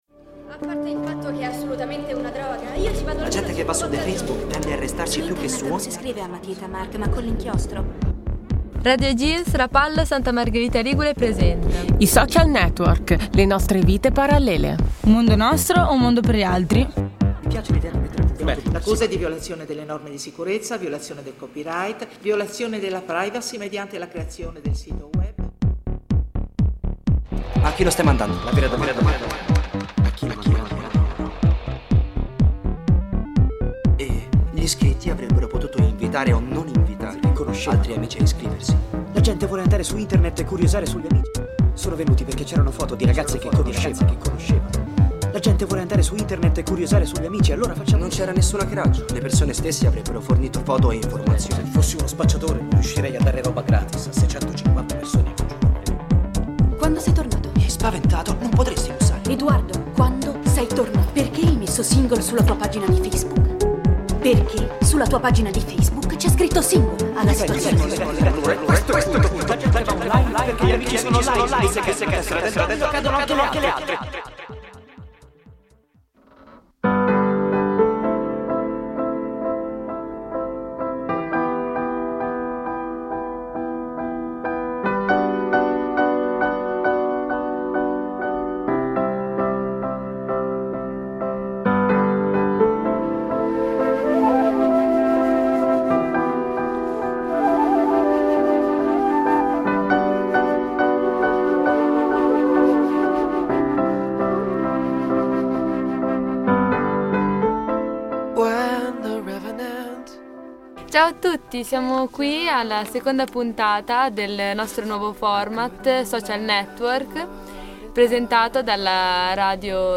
Puntata caratterizzata dall'apporto di molti partecipanti e dalla creazione di un ampio dibattito focalizzato principalmente sull'annosa questione della privacy. Nell'arco di questa puntata emergono anche i primi "scontenti" di Facebook in quanto strumento "social".